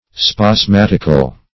Spasmatical \Spas*mat"ic*al\